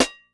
DrSnare39.wav